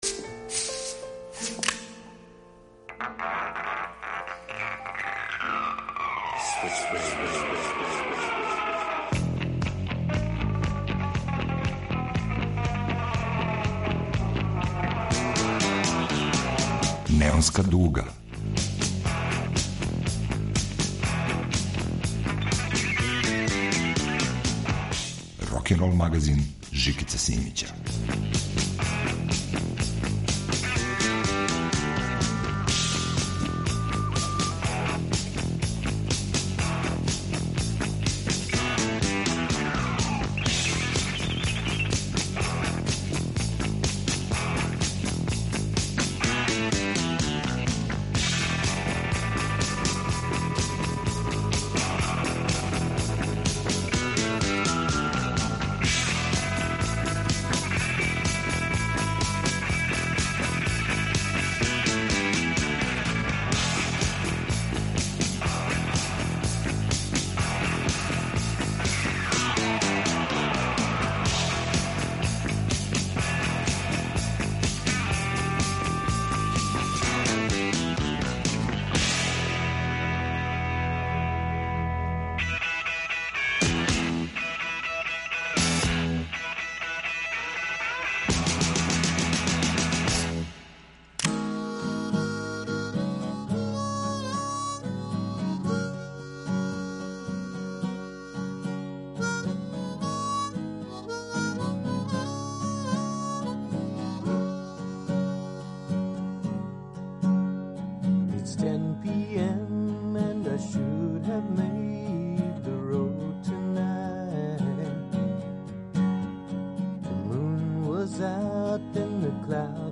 Рок музика никад није била тако суптилна.